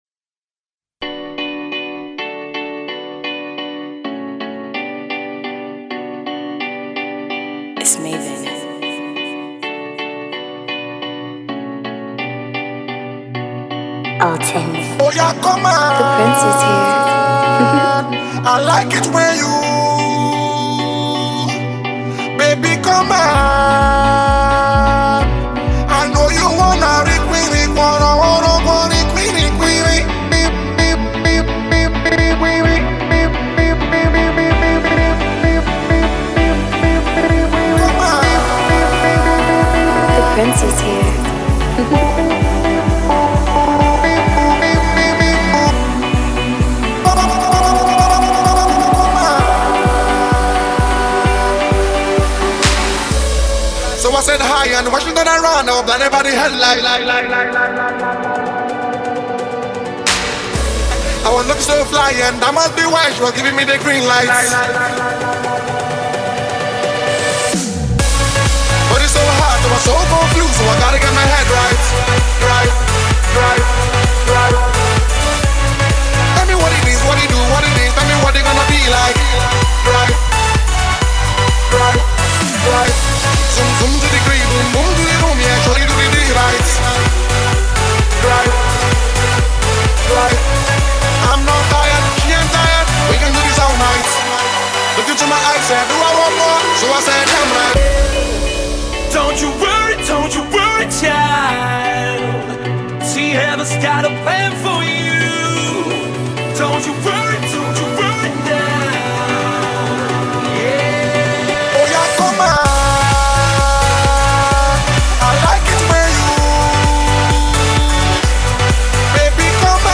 mash
the Mashup